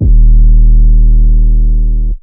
808 3 {D#} [ X ].wav